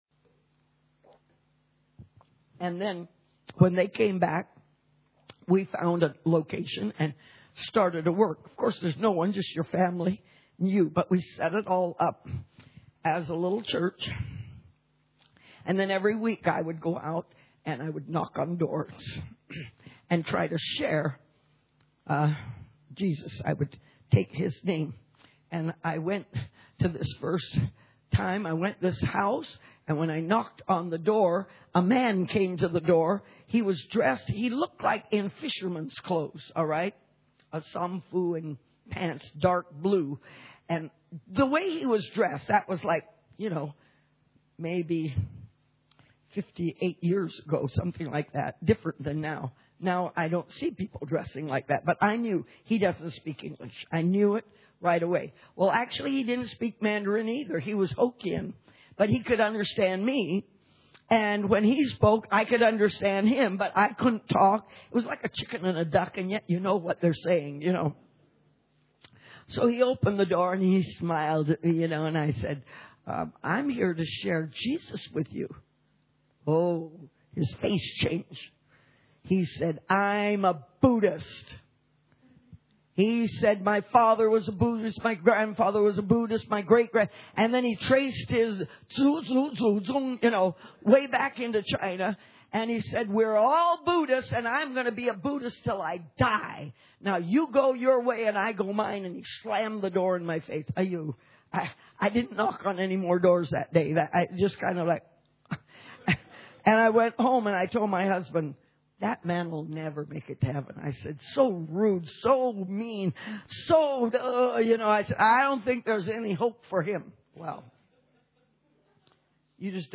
Church Camp 2012